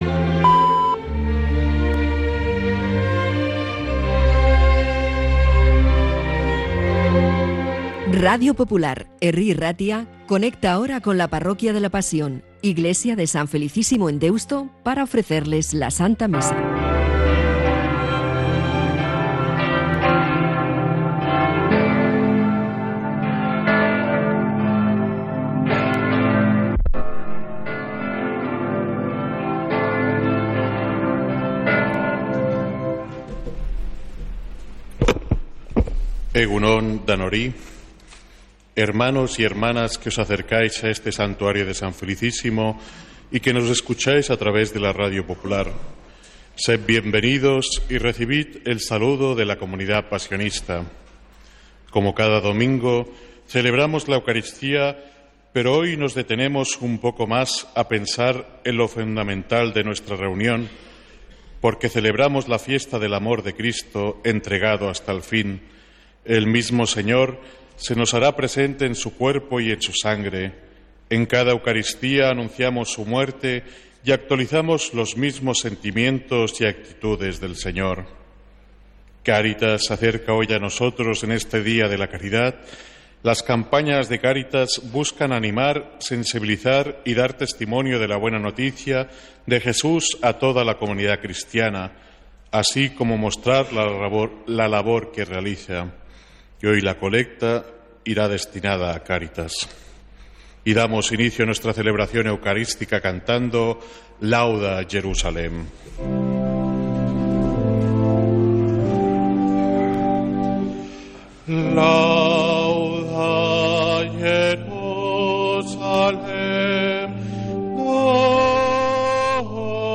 Santa Misa desde San Felicísimo en Deusto, domingo 22 de junio